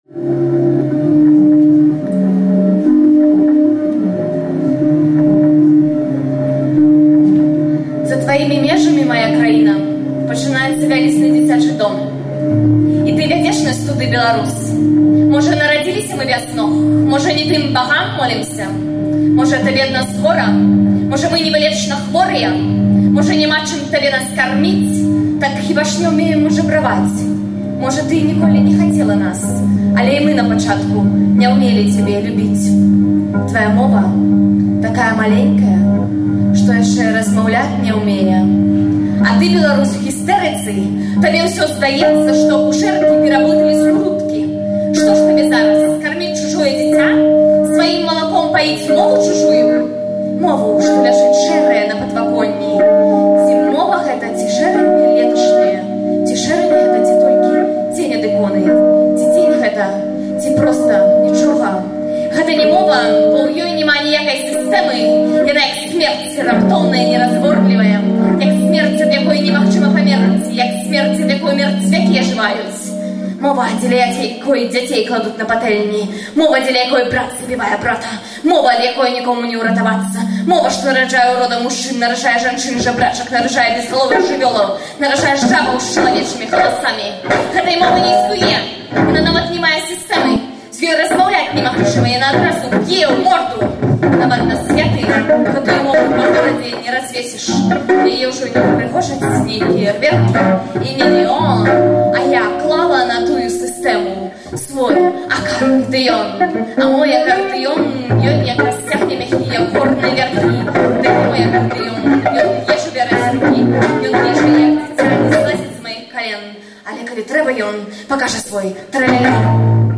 Вальжына Морт чытае свае вершы
(гітара + harmonizer + delay)
Гэта фрагмэнты выступу на фэсце “няіснае беларускае мастацтва” (20.05.06, Кемніц, Нямеччына).